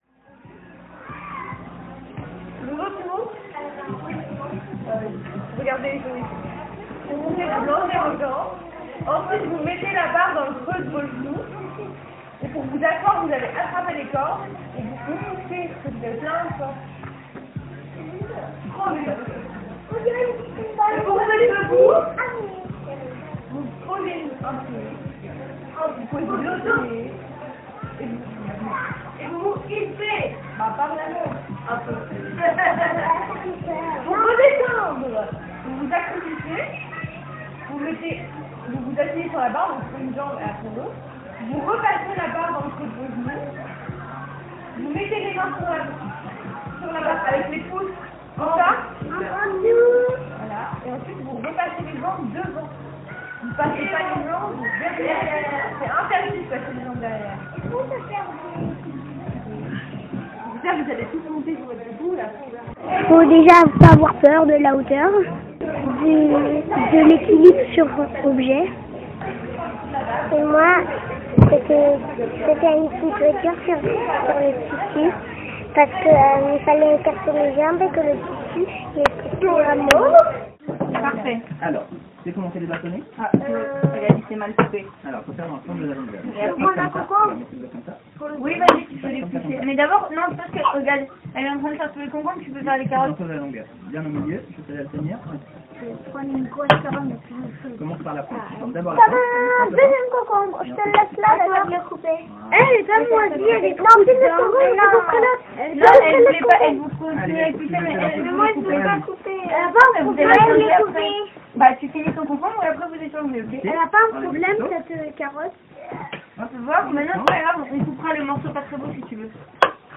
Dans cet épisode, au fil des voix, on découvre des jeux, des rires… et surtout, une activité qui a mis tout le monde d’accord : la cuisine !